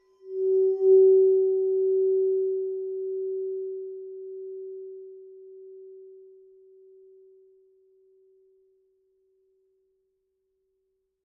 Crystal_Glass_Red_Wine_Tone_Rubbing_Musical_07.ogg